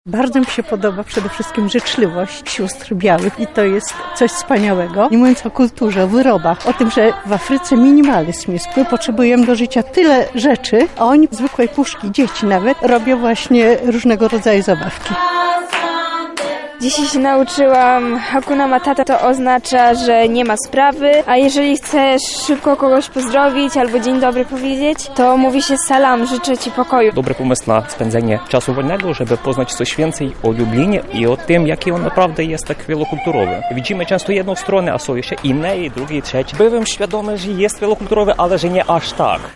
wielokulturowy relacja